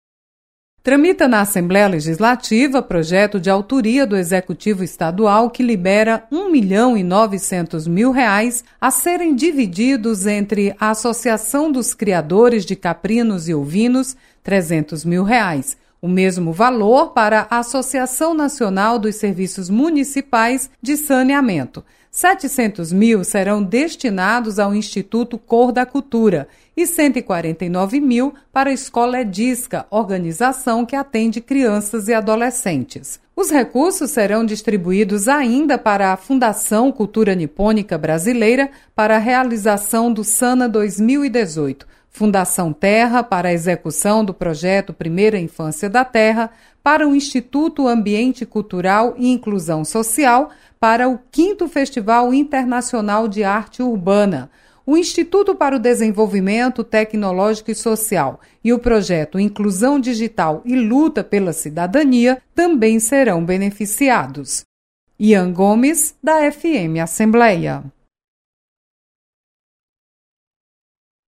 Projeto prevê transferência de recursos para saneamento e agricultura. Repórter